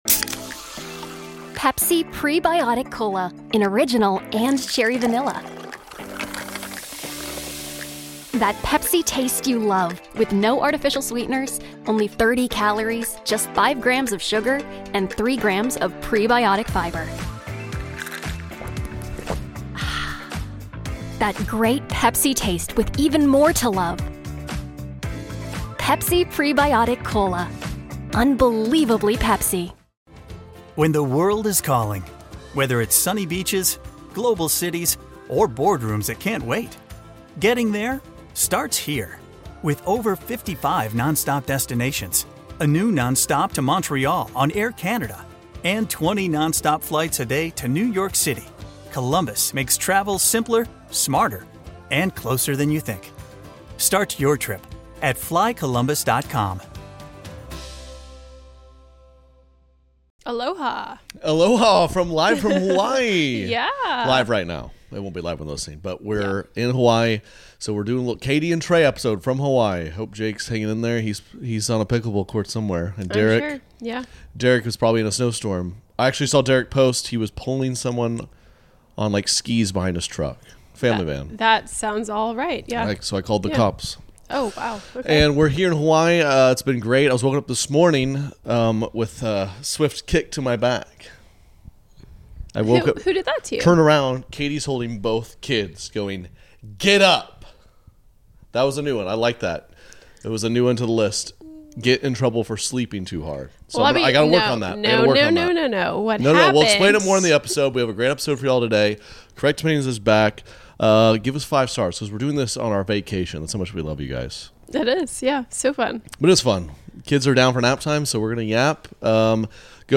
We’re recording from Hawaii and it’s the perfect storm of vacation brain, parenting chaos, and overly honest marriage talk.